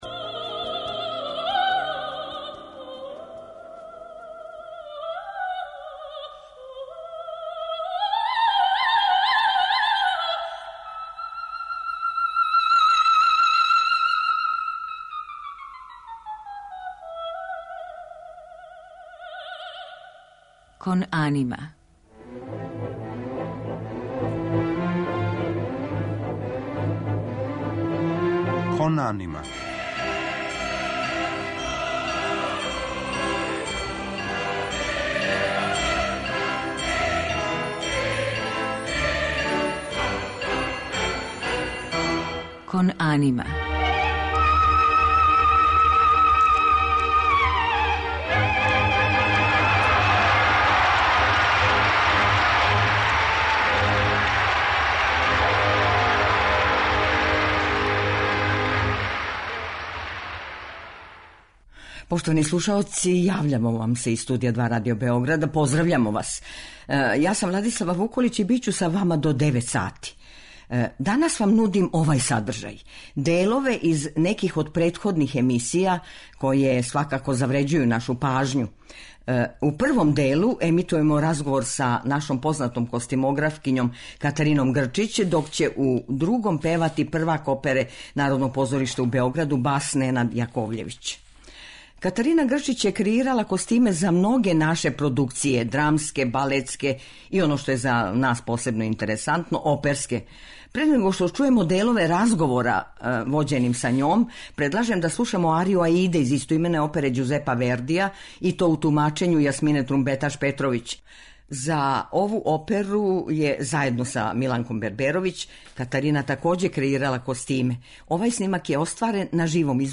У музичком делу ће бити емитовани фрагменти из Вердијевих опера "Аида", "Симон Боканегра" и "Трубадур", као и Камбасковићеве "Хасанагинице" и Фризинине "Ин хок сигно".